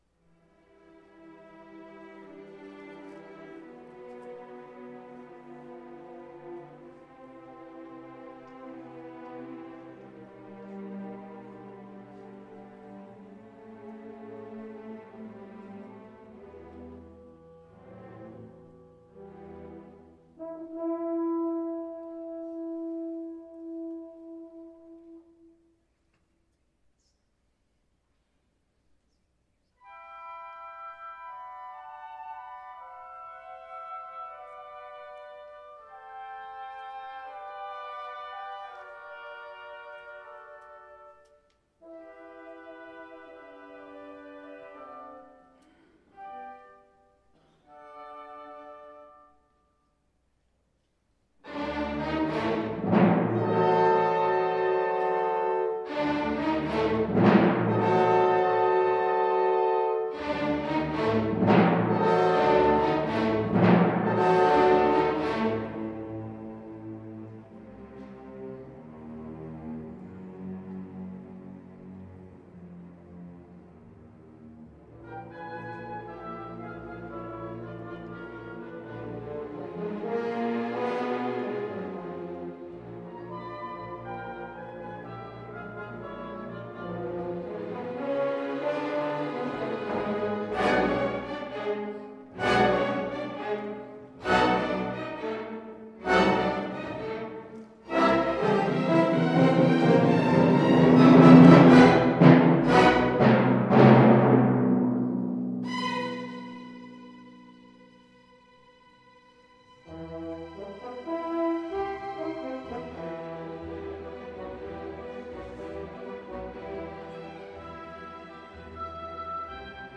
Symphony No.9, Op.95 'From the New World' Orchestra
Style: Classical